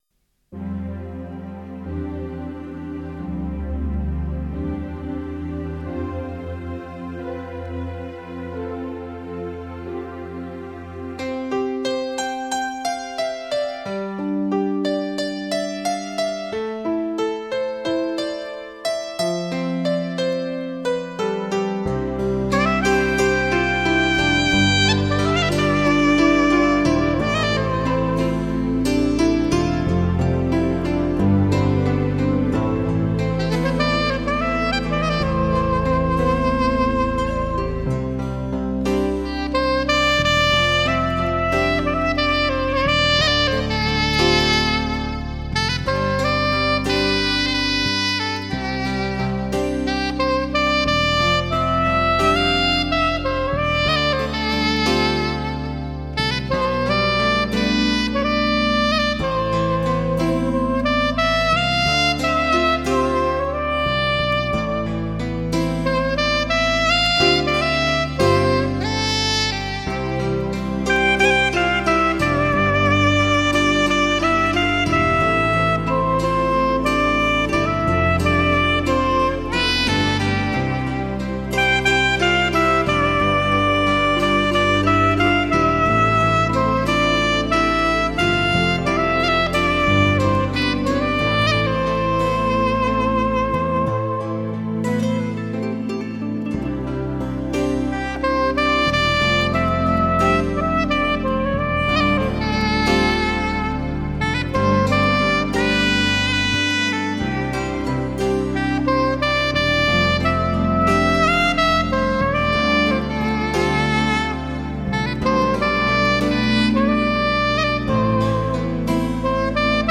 MUSIC OF POP